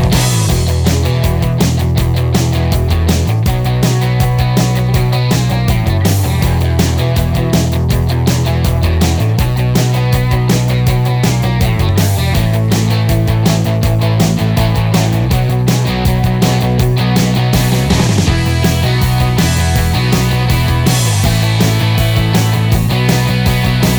no Backing Vocals Soft Rock 3:17 Buy £1.50